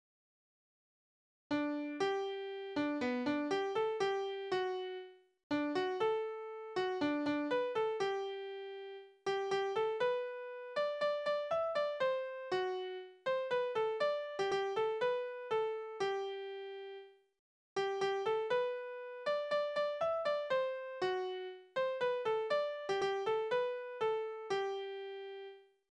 Politische Lieder:
Tonart: G-Dur
Taktart: 4/4
Tonumfang: Undezime
Besetzung: vokal